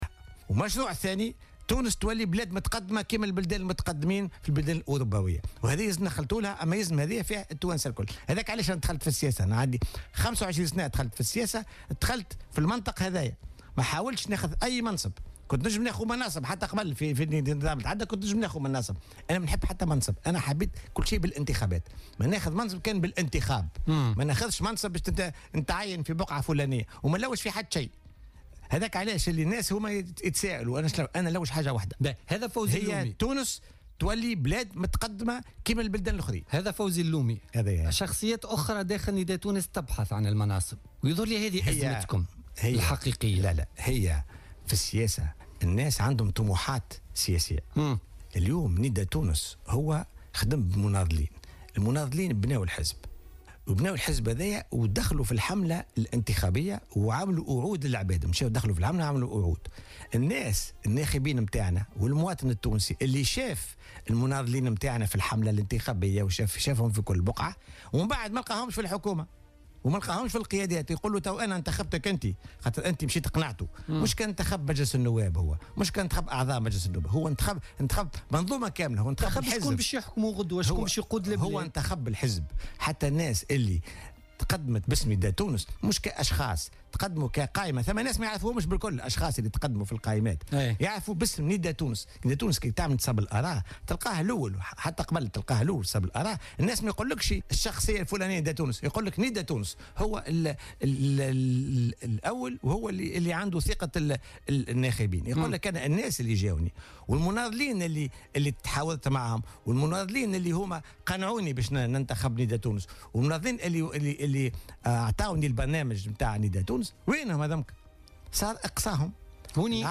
أكد فوزي اللومي عضو الهيئة السياسية والعضو في مجموعة الإنقاذ لنداء تونس ضيف بوليتيكا اليوم الإثنين 31 أكتوبر 2016 أنه دخل السياسة بعيدا عن منطق المناصب ولم يكن يرغب في أن يتحمل أي مسؤولية دون انتخابات موضحا أنه لا يبحث إلا عن مصلحة تونس وأن تصبح مثل أي دولة متقدمة مثل الدول الأوروبية.